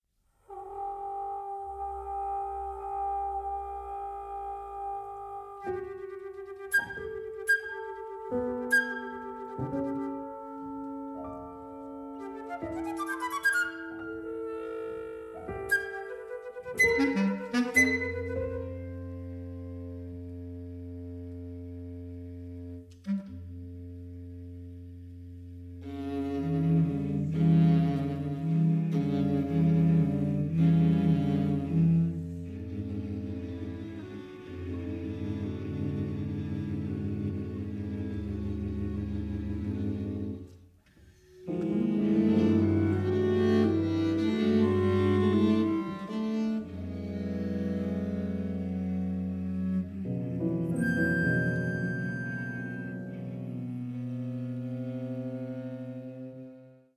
flute, piccolo
bass clarinet, clarinet
trombone
piano
viola
cello